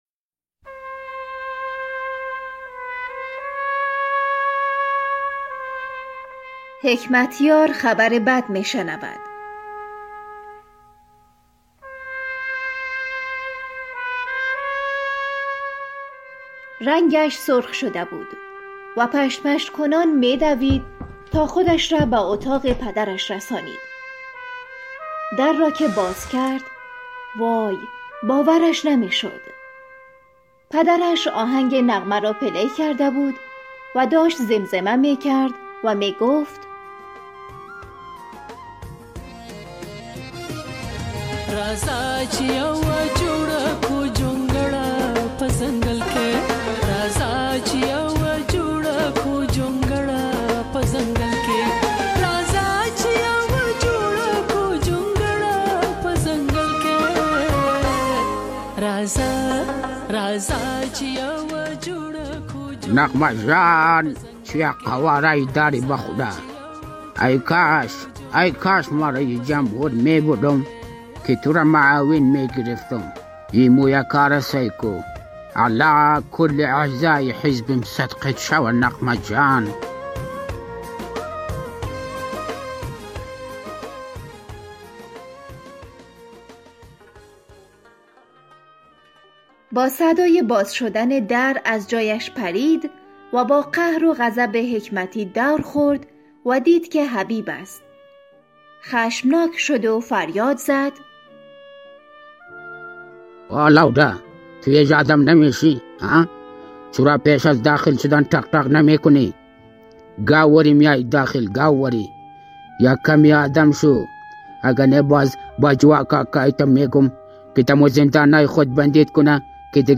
پادکست «طنز آرا» با اجراي تيمي از استندآپ كمدين ها و طنز پردازان خوب افغانستاني تهيه مي شود